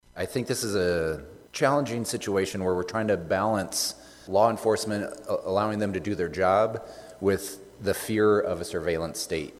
REPRESENTATIVE J.D. SCHOLTEN OF SIOUX CITY SAYS THE BILL ISN’T PERFECT, BUT IT PROVIDES PROTECTIONS THAT AREN’T IN PLACE NOW.